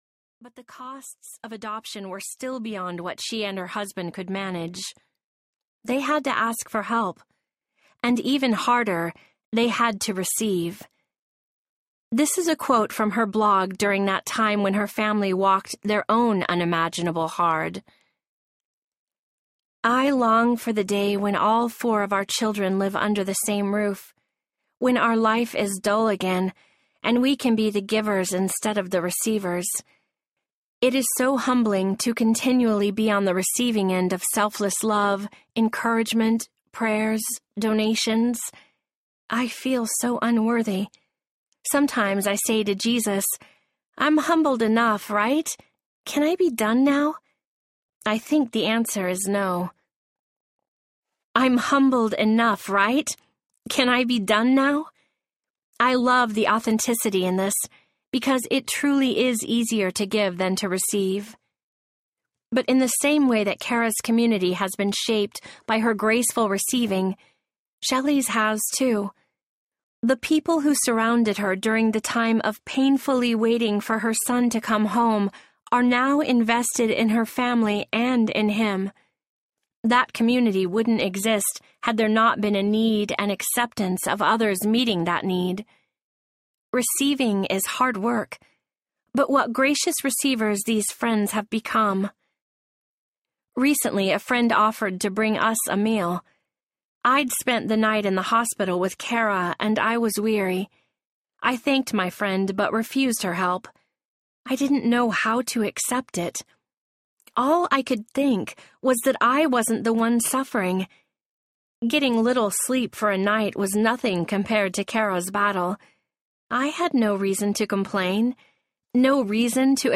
Just Show Up Audiobook
Narrator
4.00 Hrs. – Unabridged